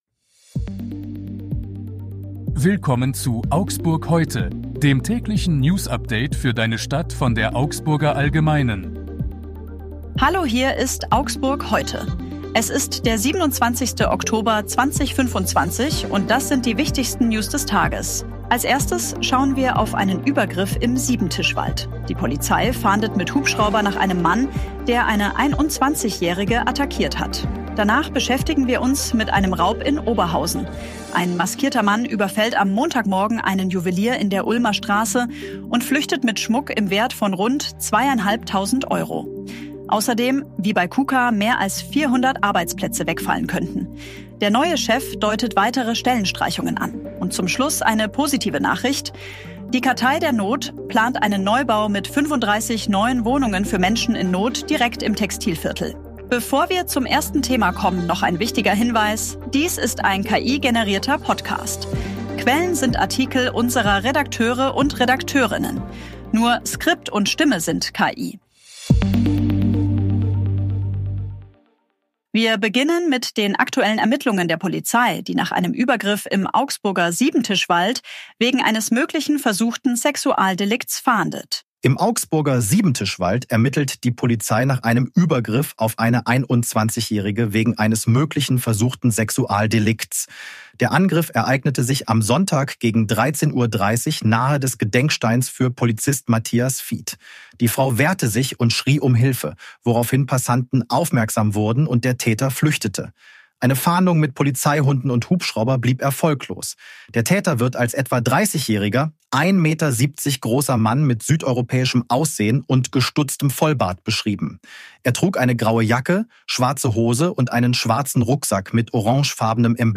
Bedürftige Dies ist ein KI-generierter Podcast.